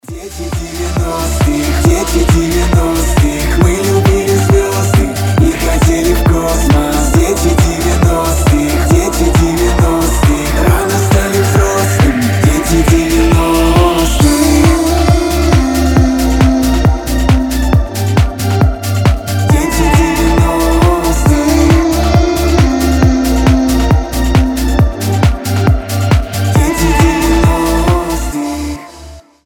• Качество: 320, Stereo
ностальгия
евродэнс